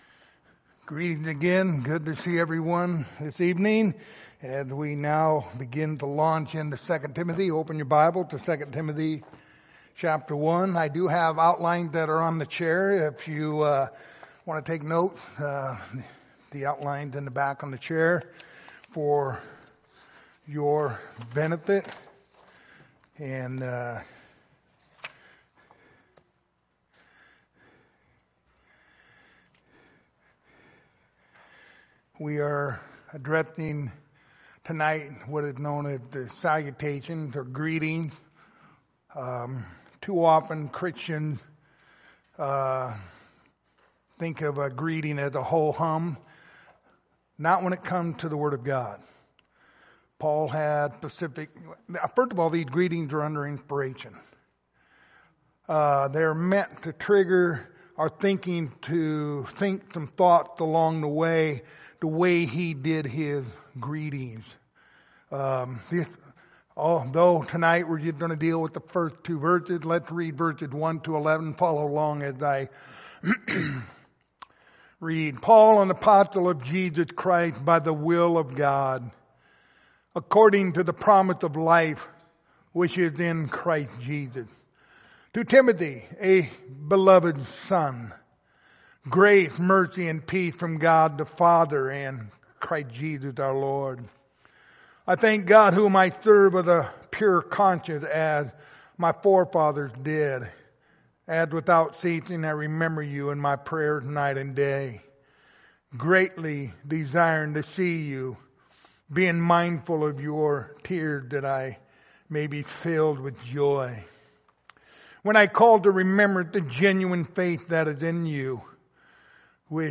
Pastoral Epistles Passage: 2 Timothy 1:1-2 Service Type: Sunday Evening Topics